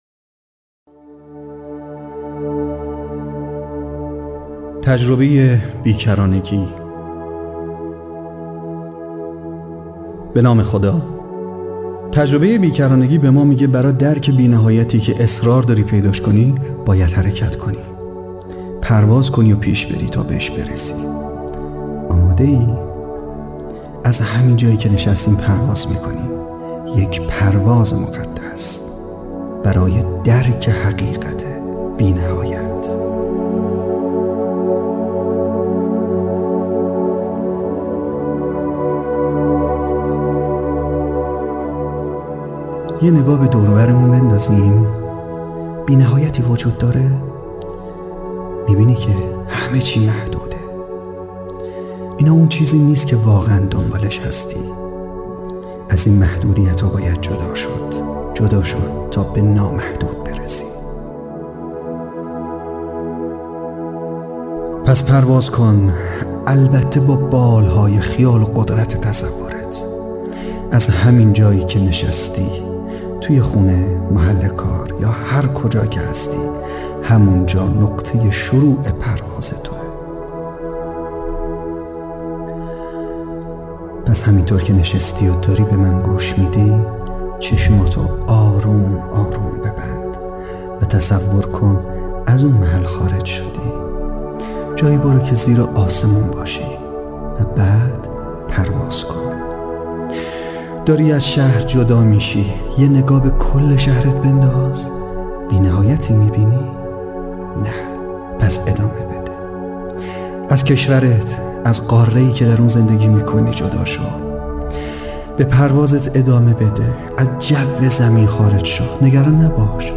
آموزش صوتی مهارتهای زندگی ( خودآگاهی)